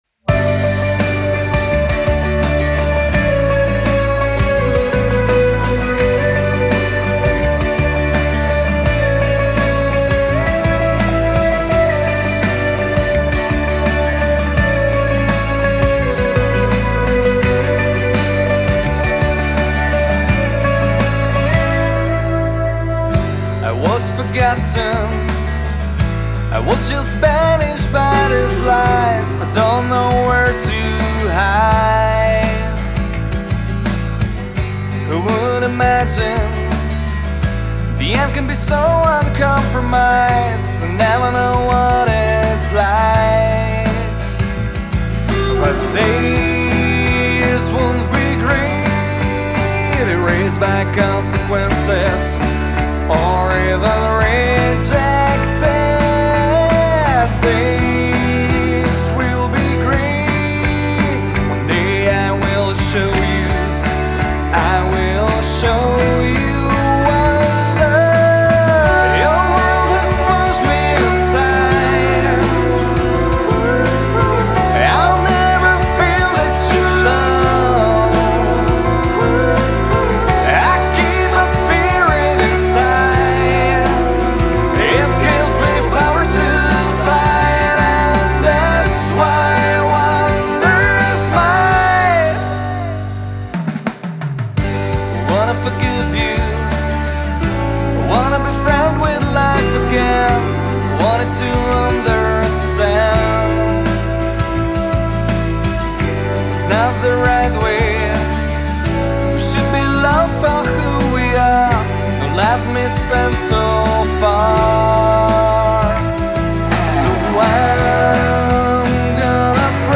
gitary
basgitary, spevy
klávesy
bicie